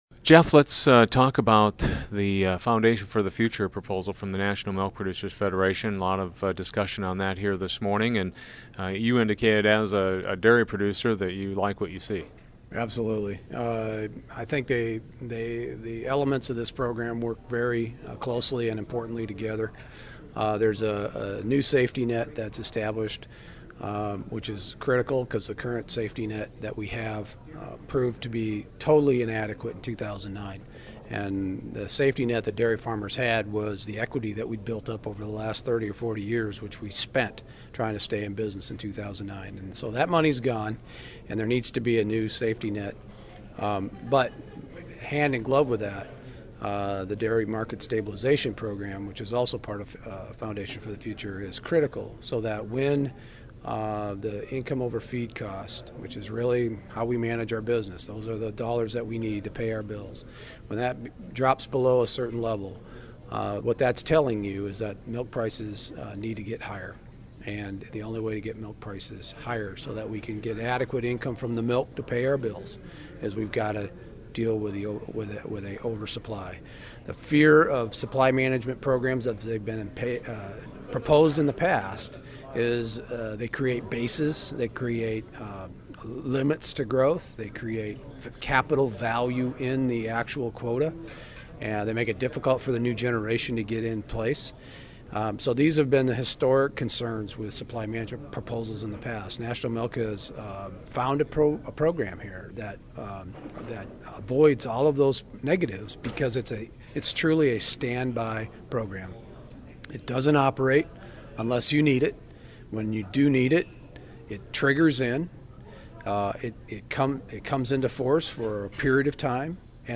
At a recent dairy conference in Omaha